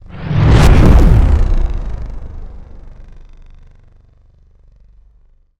sci-fi_vehicle_thrusters_engage_01.wav